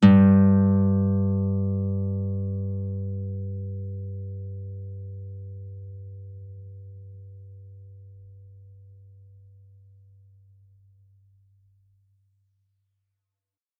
guitar-acoustic
G2.wav